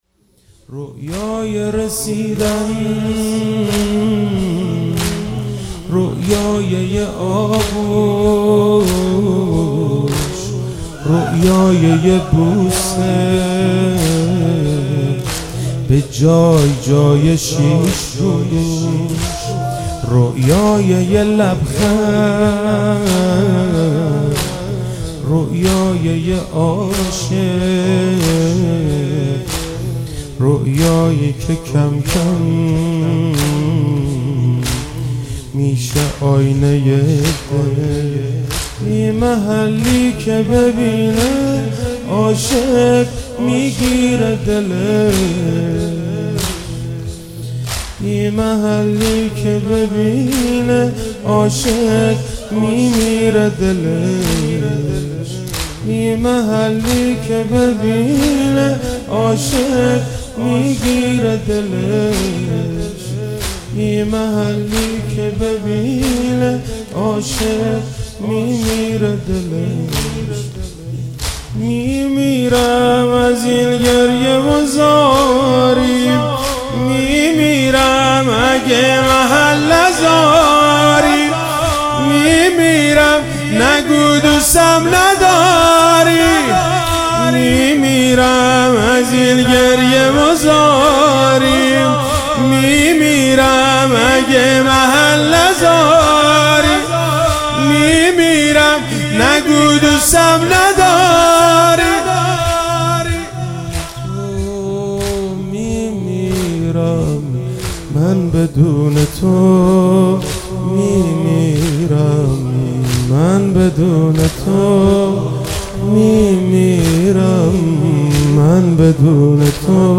مناجات با امام حسین (ع)